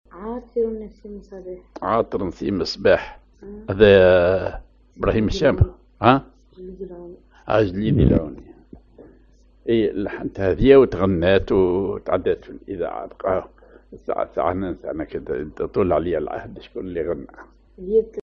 Maqam ar بياتي
genre أغنية